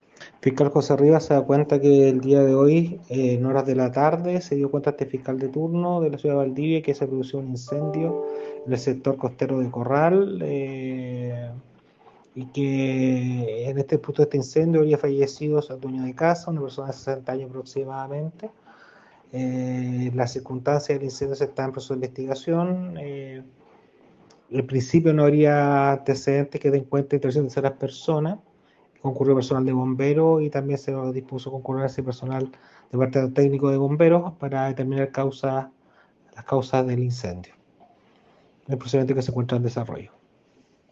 fiscal José Rivas sobre la investigación que dirige la Fiscalía de Valdivia por la muerte de un hombre en un incendio en la comuna de Corral.